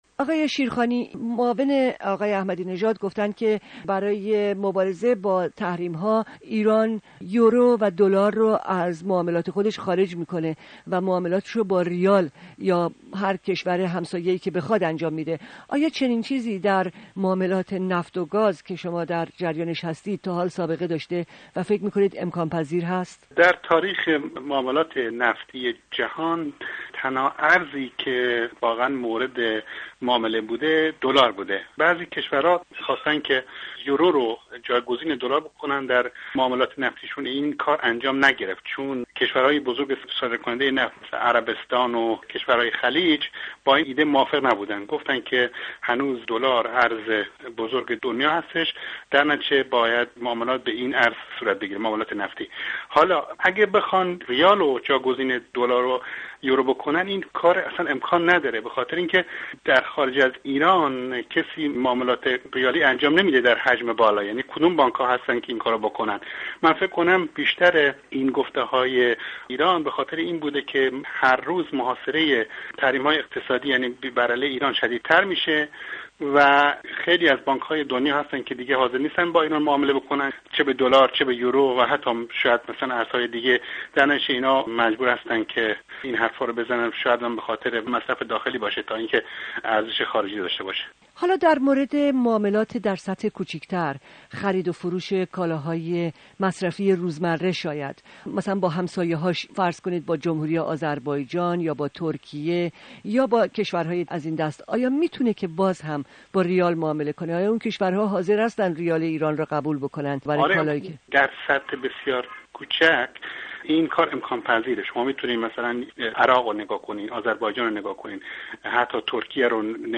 گفت و گوی